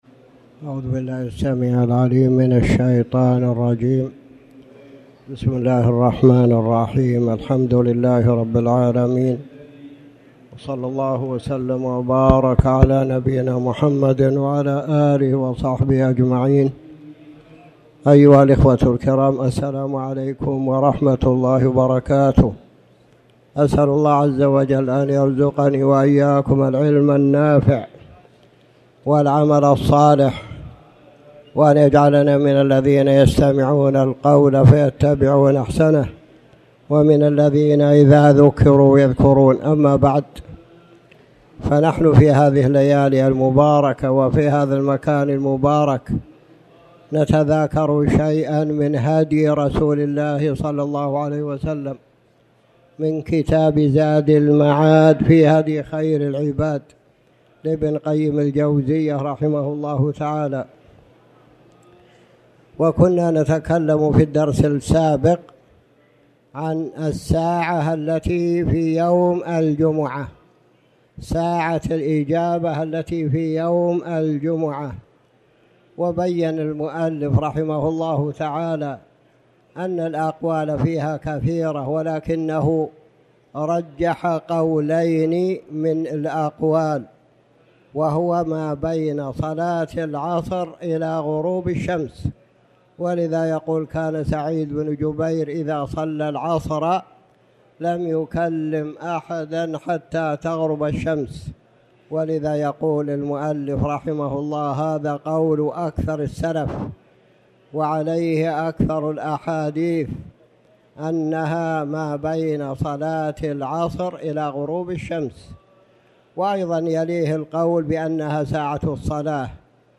تاريخ النشر ٢٢ ذو الحجة ١٤٣٩ هـ المكان: المسجد الحرام الشيخ